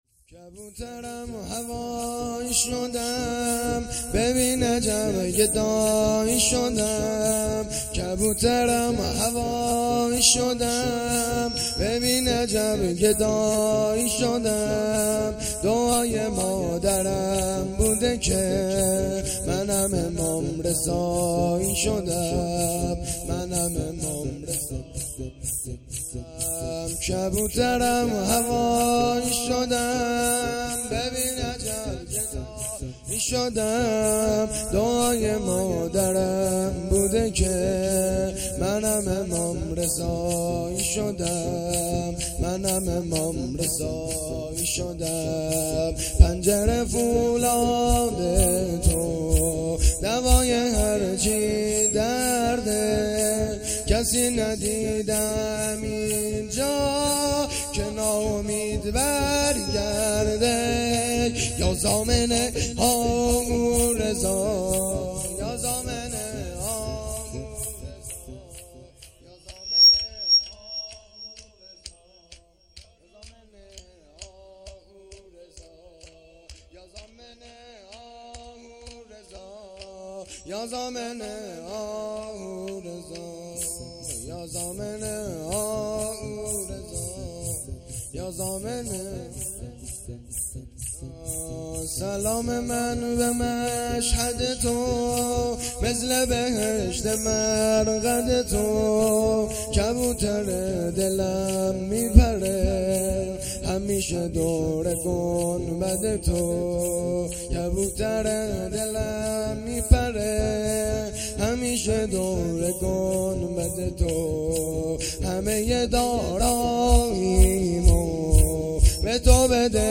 شور
دوشنبه ۱۲ مهر ۱۴۰۰- هیئت جوانان ریحانه الحیدر سلام الله علیها